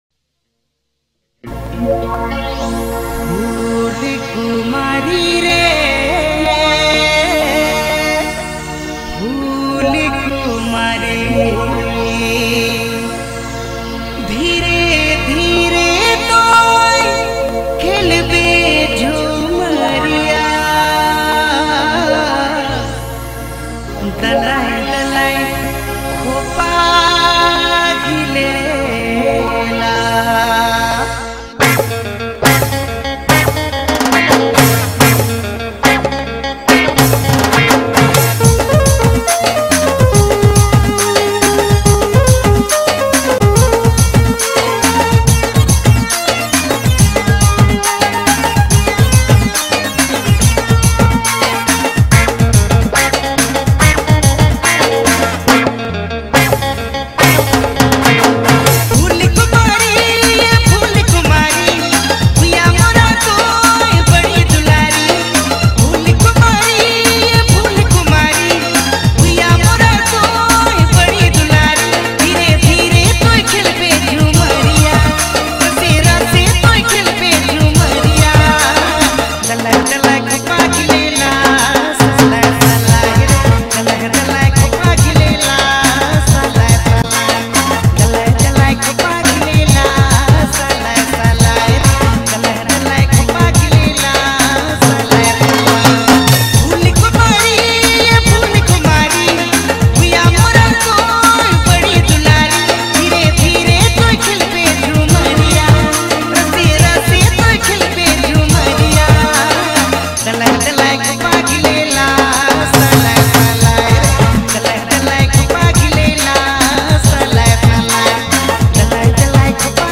soulful Nagpuri DJ remix song